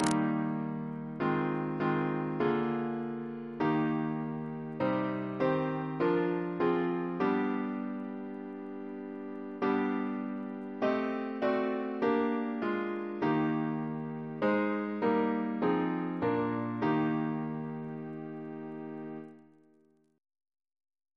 Double chant in E♭ Composer: George Thalben-Ball (1896-1987), Organist of the Temple Church Reference psalters: RSCM: 135